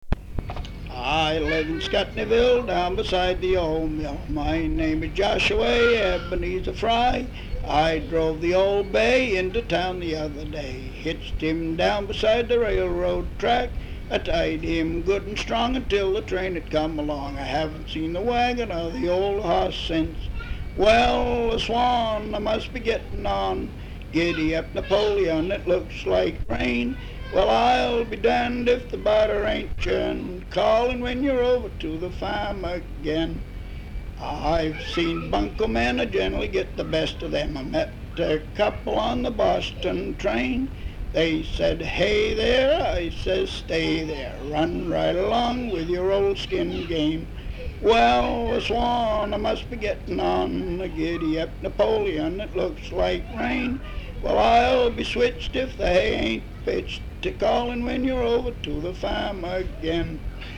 Folk songs, English--Vermont
sound tape reel (analog)
Location Ascutney, Vermont